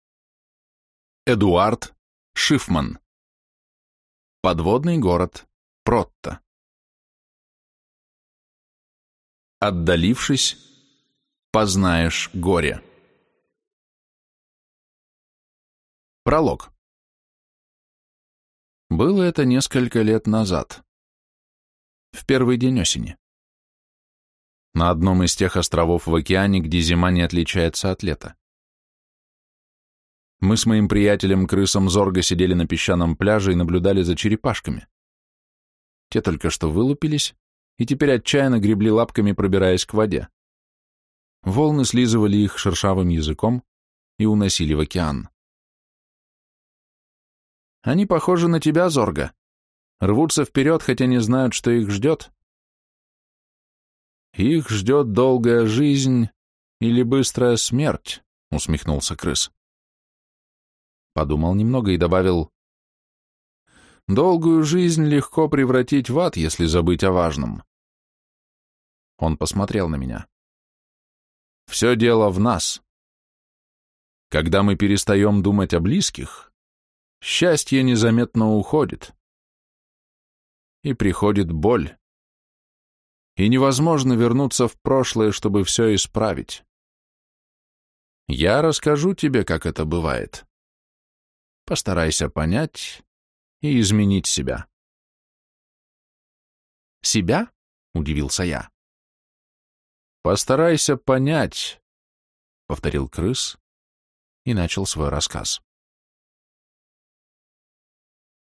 Аудиокнига Подводный город Протто | Библиотека аудиокниг